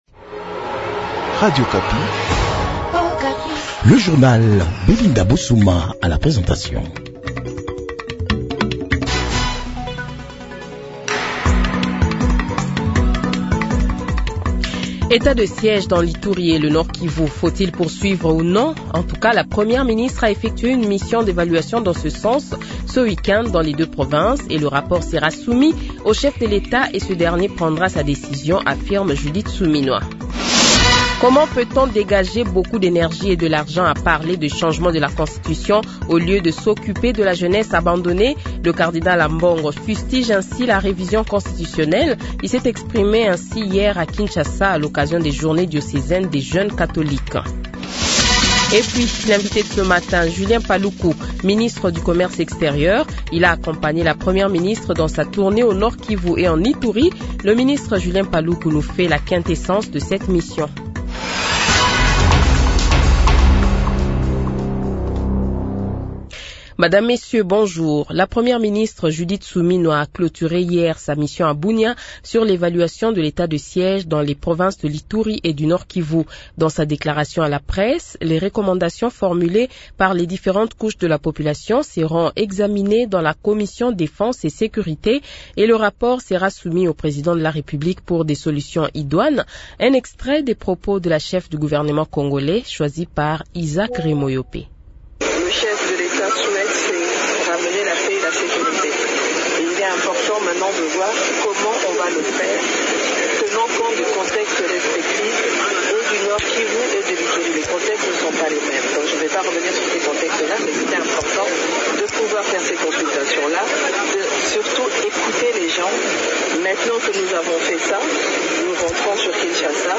Le Journal de 7h, 25 Novembre 2024 :